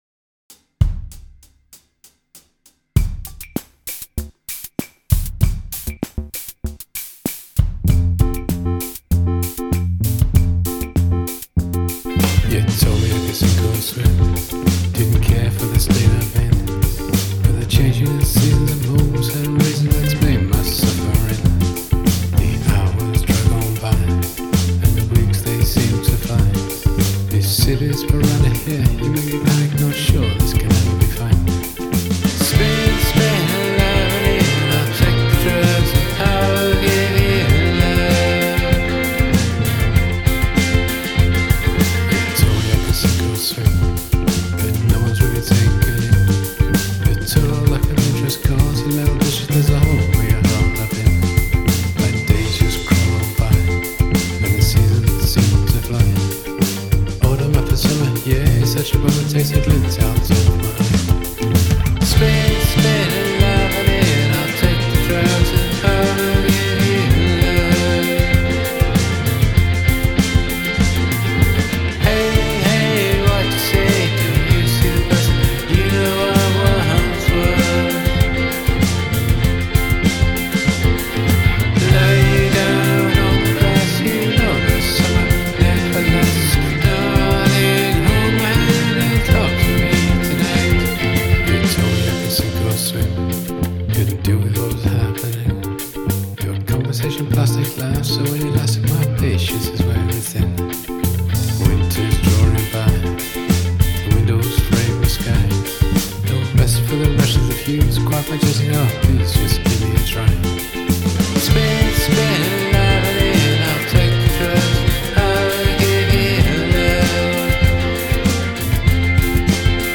I like the contrast between vocals in verse/chorus.
Brings urgency to the chorus.
It's cool how it starts out that way and then layers on bigger drums etc. I wish the vocals had a little more clarity - I'm not averse to a little vocal obfuscation but this song has a really nice soaring chorus melody and I think it could be a little more of a feature rather than getting a little swamped by the mix.